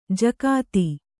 ♪ jakāti